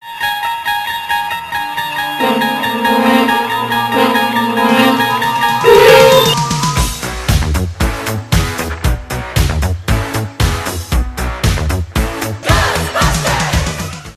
Une ambiance étrange